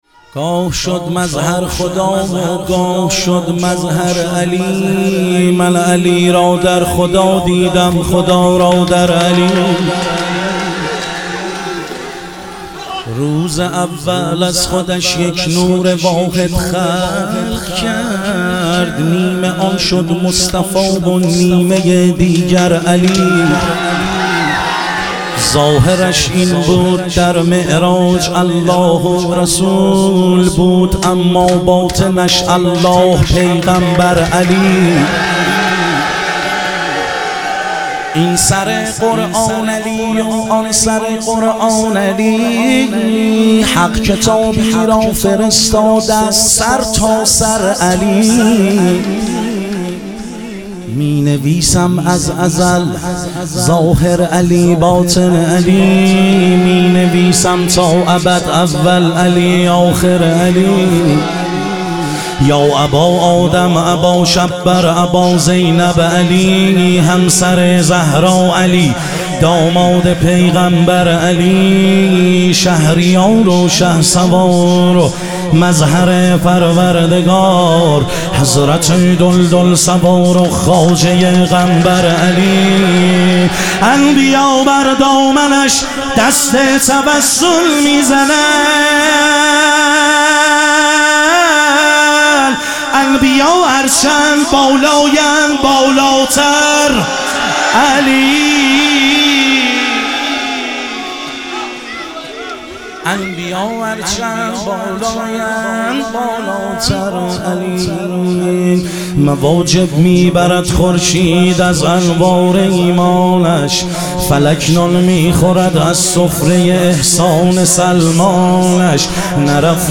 مدح و رجز
شب شهادت حضرت سلطانعلی علیه السلام